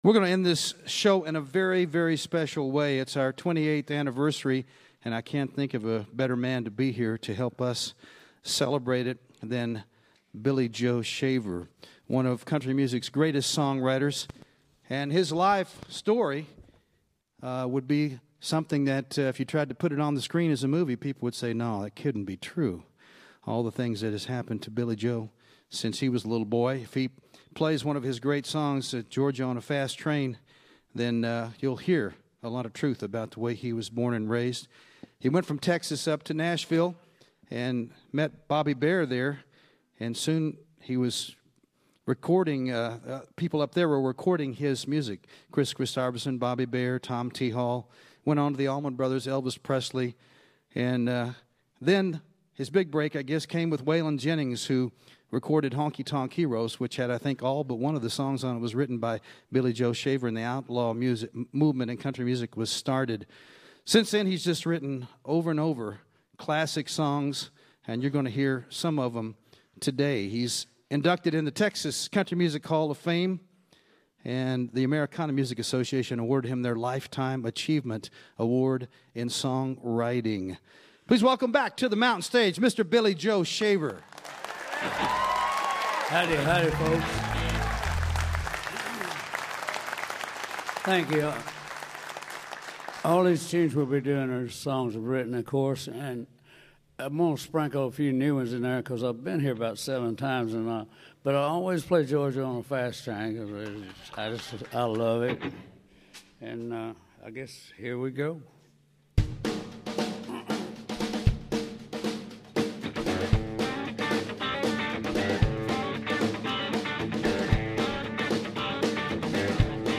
kicking off the outlaw country movement.